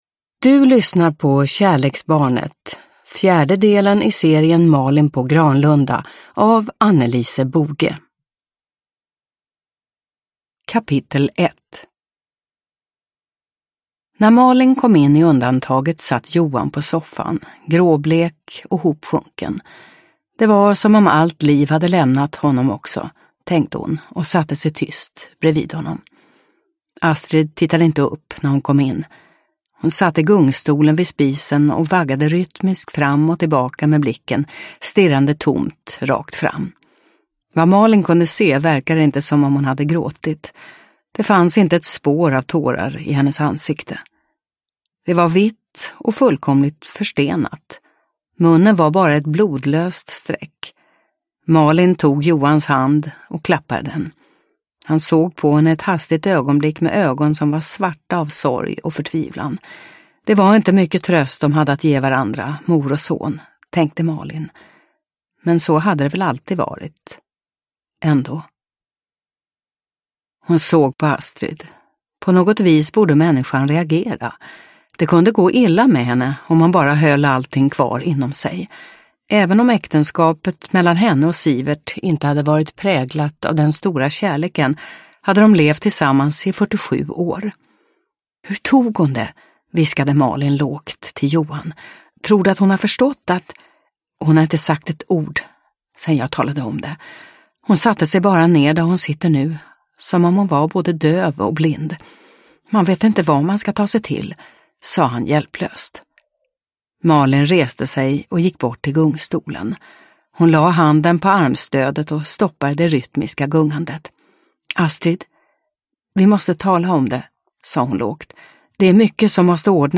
Kärleksbarnet – Ljudbok – Laddas ner